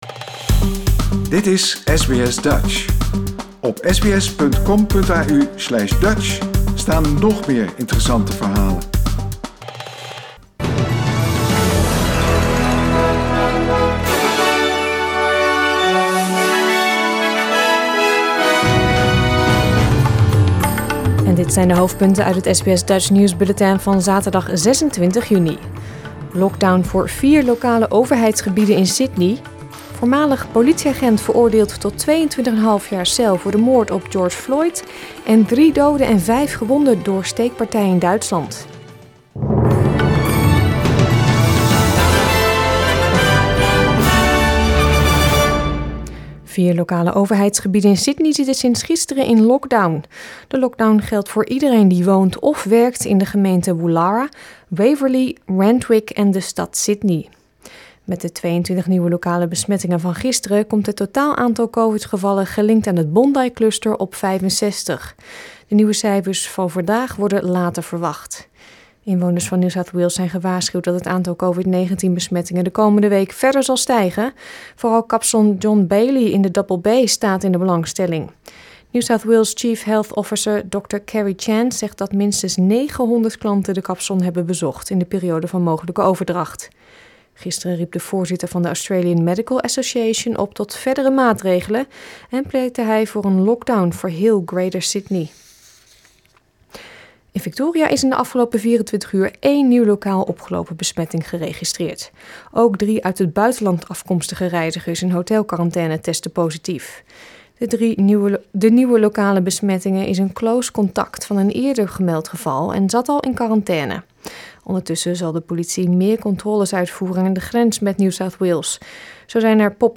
Nederlands/Australisch SBS Dutch nieuwsbulletin van zaterdag 26 juni 2021